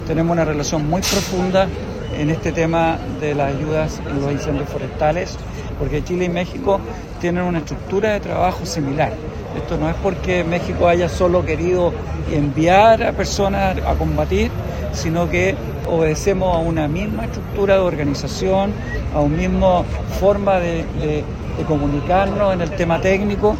Por ello, se llevó a cabo una ceremonia en agradecimiento y despedida de los brigadistas.
Por su parte, desde Conaf, el director regional en el Bío Bío, Esteban Krause, subrayó la labor coordinada con el equipo chileno.